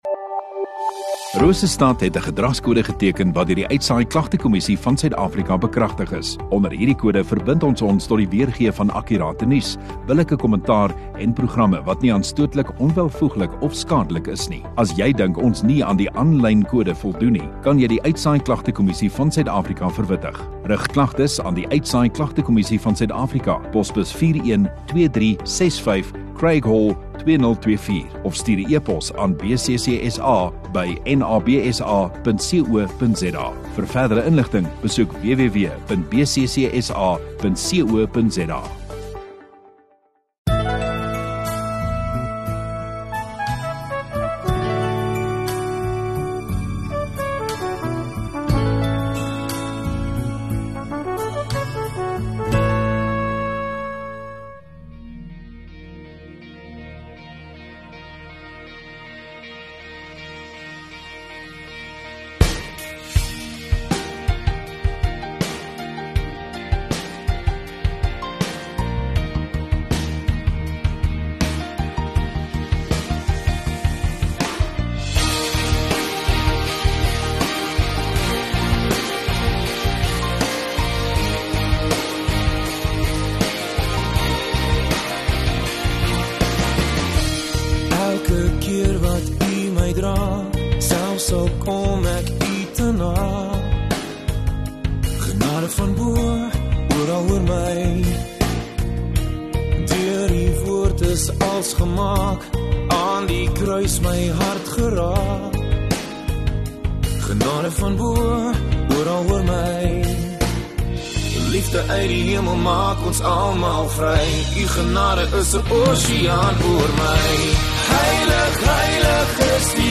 21 Feb Saterdag Oggenddiens